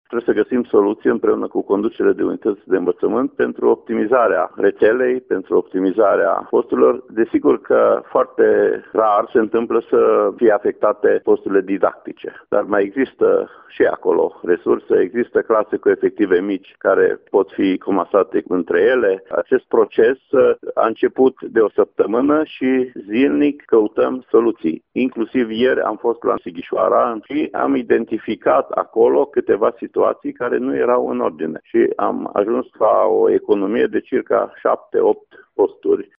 Cei mai afectați vor fi personalul nedidactic și didactic auxiliar, a declarat pentru RTM inspectorul școlar general al judeţului Mureș, Ștefan Someșan: